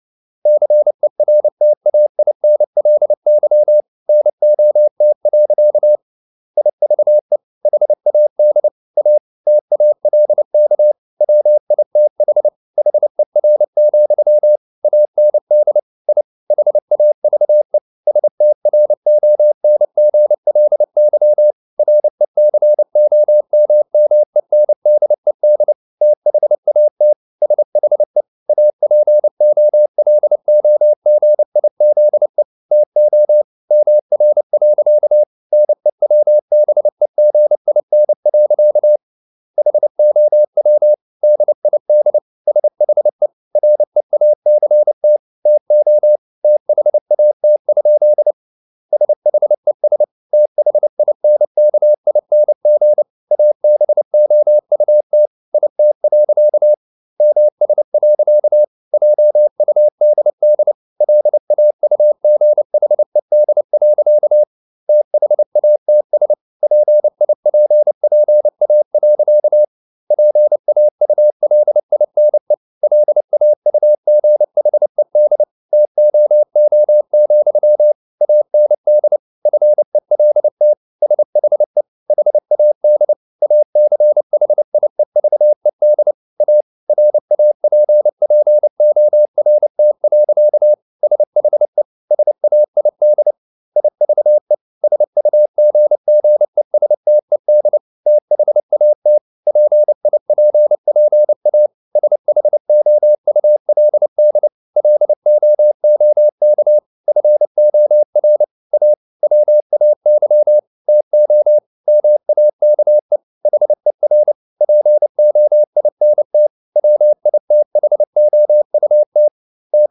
Never 29wpm | CW med Gnister
Never_0029wpm.mp3